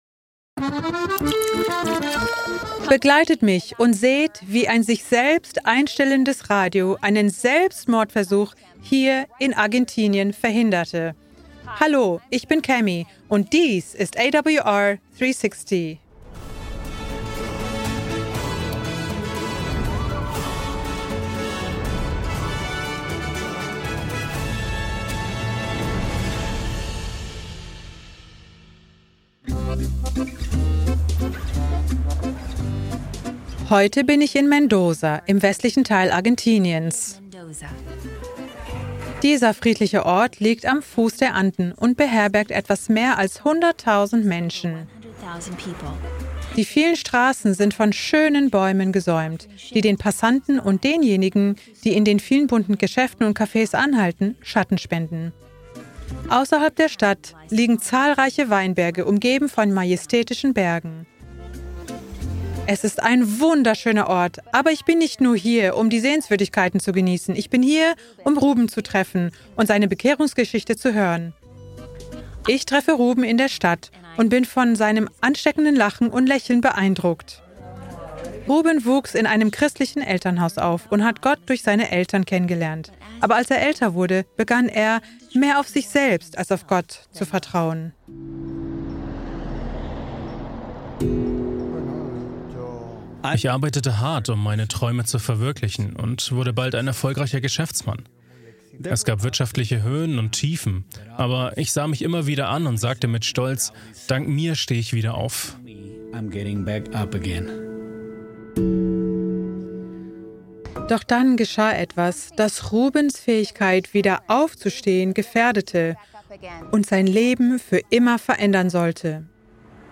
Kategorie Zeugnis